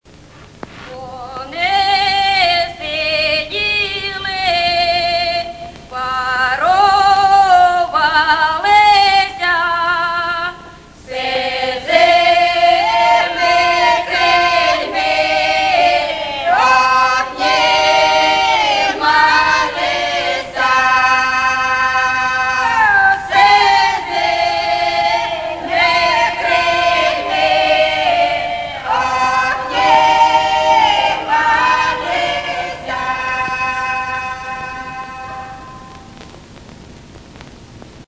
Рингтоны » Саундтреки